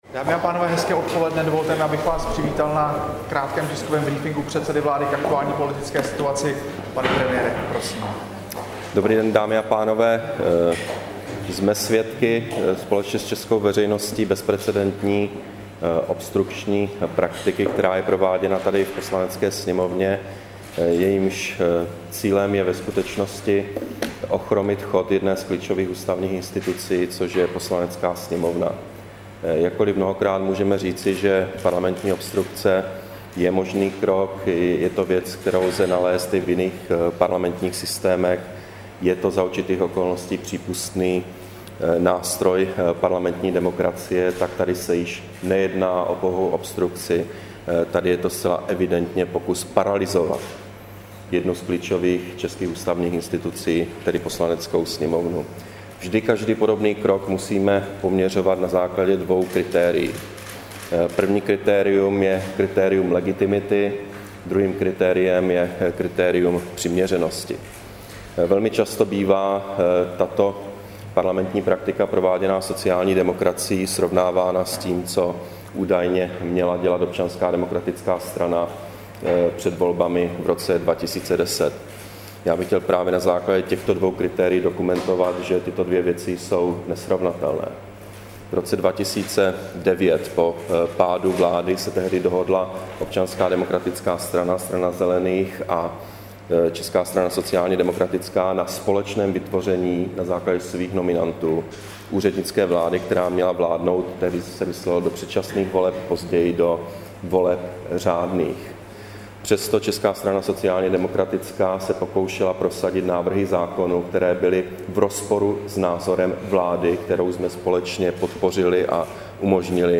Brífink premiéra k obstrukcím opozice ve sněmovně, 3. listopadu 2011